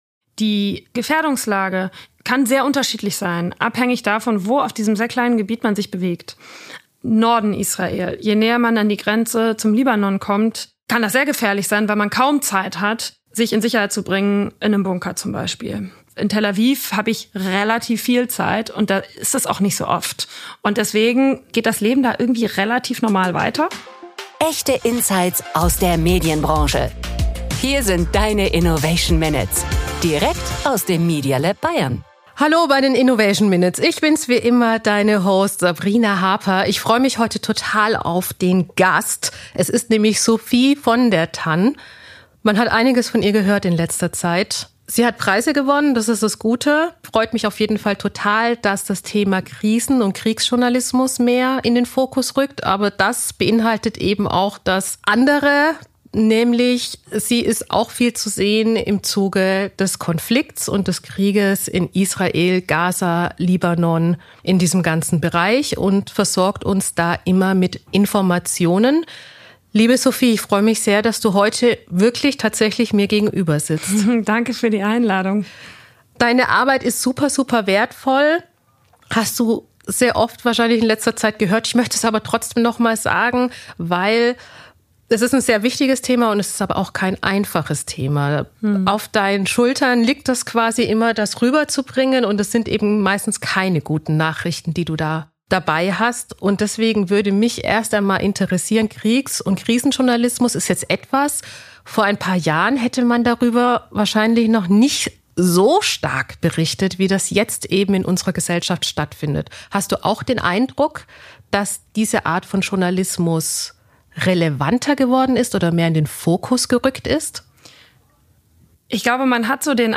Krisenjournalismus und Kriegsberichterstattung sind in den vergangenen Jahren intensiver und unverzichtbarer geworden. Im Gespräch ist Kriegs- und Krisenreporterin Sophie von der Tann und spricht über die einzigartigen Herausforderungen und ethischen Fragen in diesem Metier.